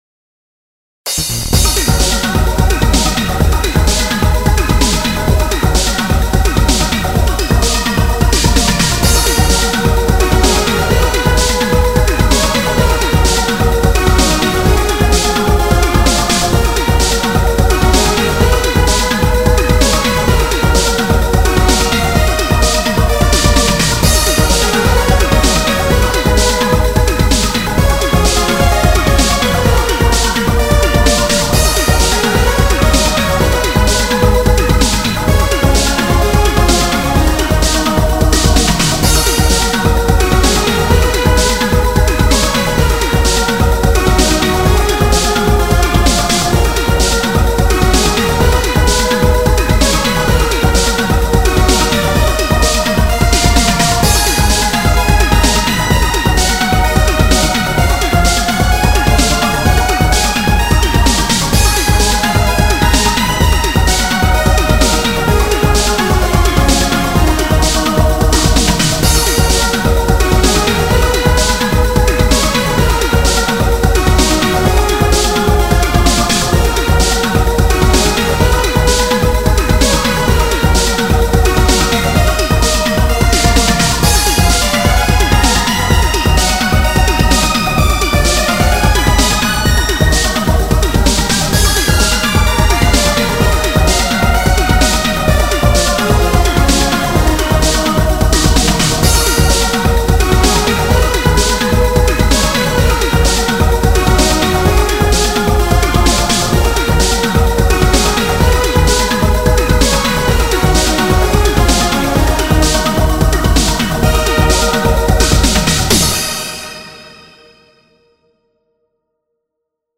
BGM
EDMアップテンポ激しい